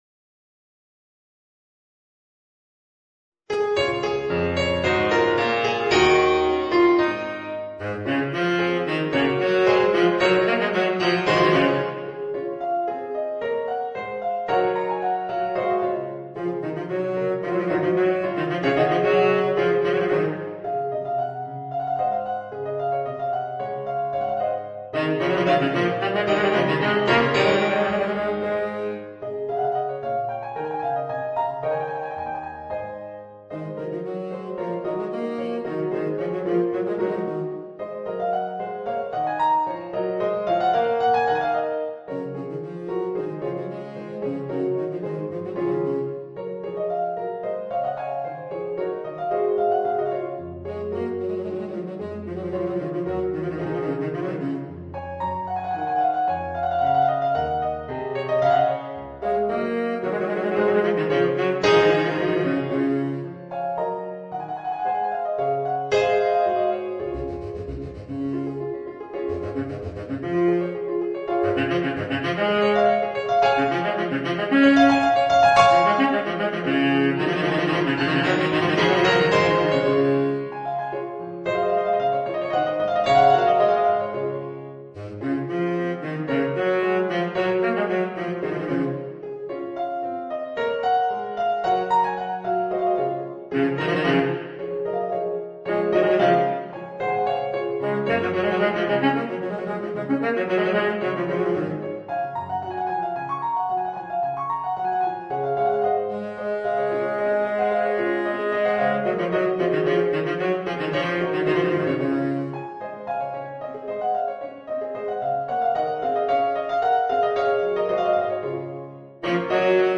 Voicing: Baritone Saxophone and Organ